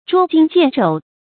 捉衿见肘 zhuō jīn jiàn zhǒu
捉衿见肘发音
成语注音 ㄓㄨㄛ ㄐㄧㄣ ㄒㄧㄢˋ ㄓㄡˇ